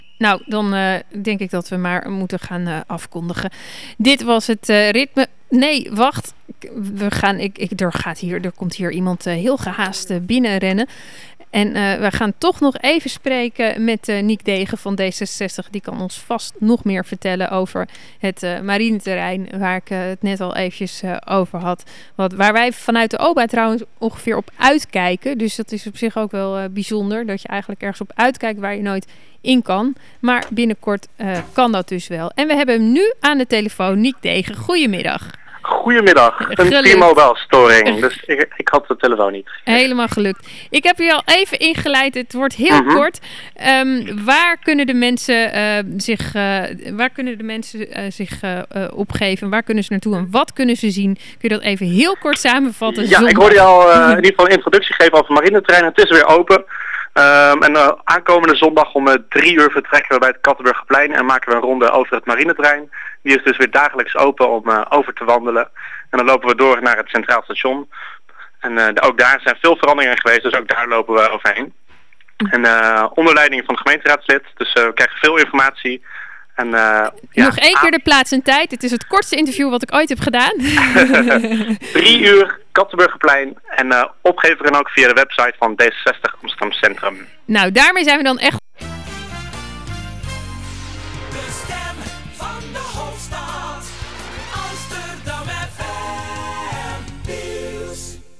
Het kortste interview uit de geschiedenis van Ritme.